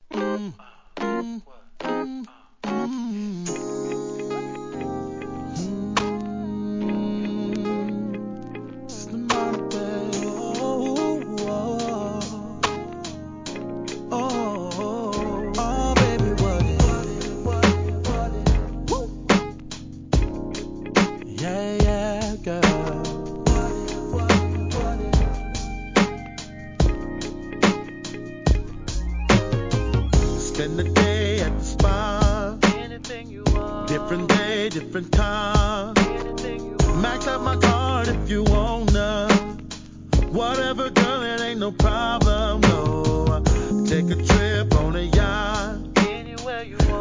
HIP HOP/R&B
奥深く、それでいて優しいヴォーカルでドラマチックに歌い上げる絶品R&B!!!